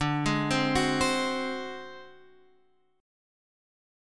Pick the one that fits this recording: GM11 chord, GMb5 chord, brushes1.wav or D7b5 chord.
D7b5 chord